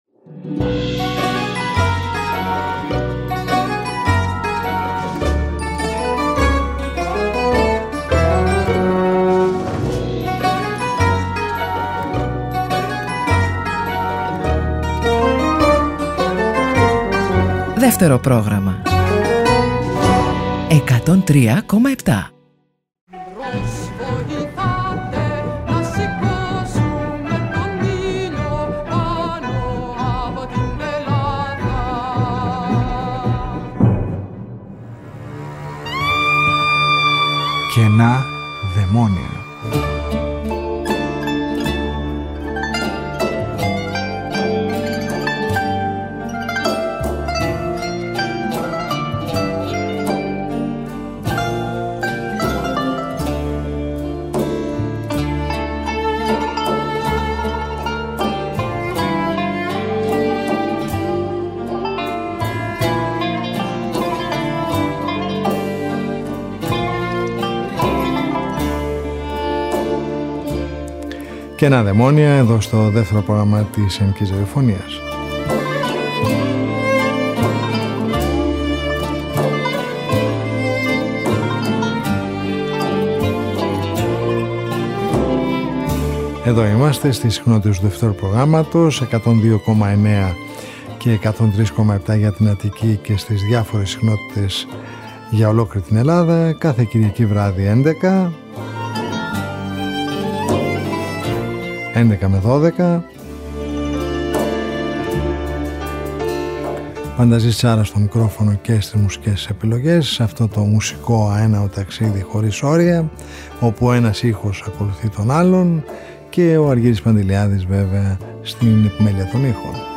Μια ραδιοφωνική συνάντηση κάθε Κυριακή στις 23:00 που μας οδηγεί μέσα από τους ήχους της ελληνικής δισκογραφίας του χθες και του σήμερα σε ένα αέναο μουσικό ταξίδι.